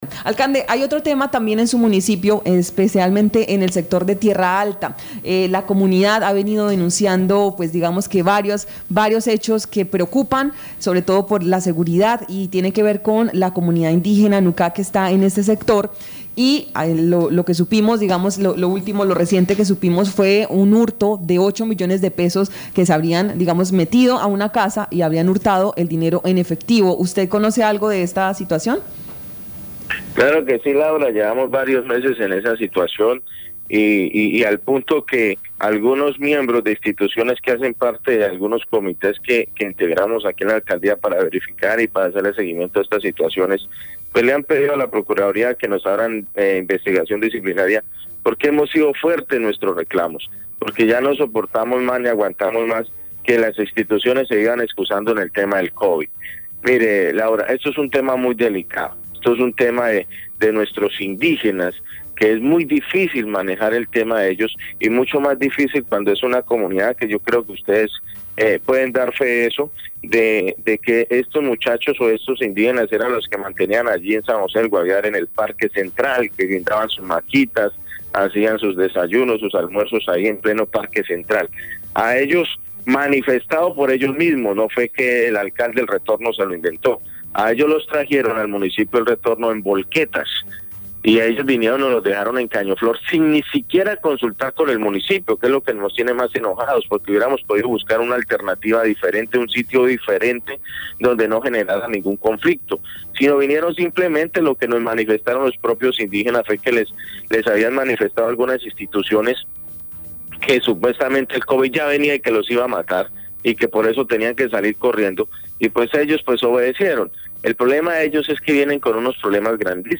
Escuche a Yeison Pineda, alcalde de El Retorno, Guaviare.